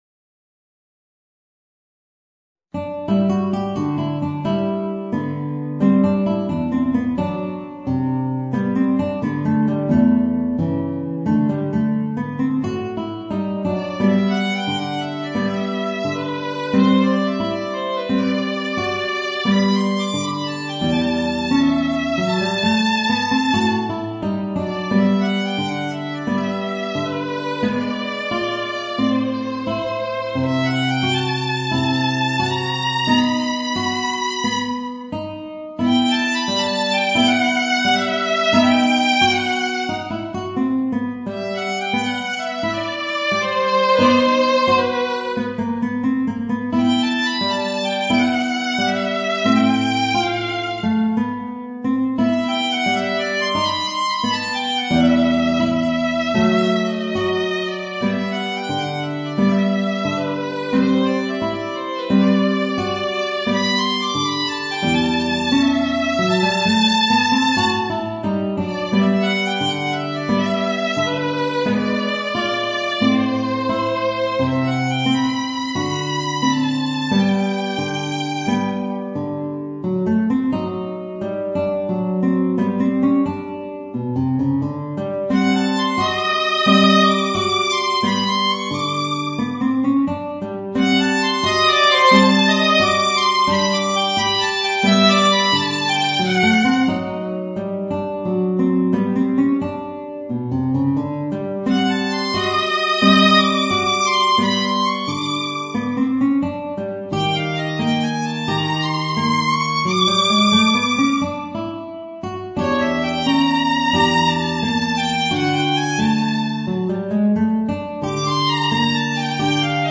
Voicing: Violin and Guitar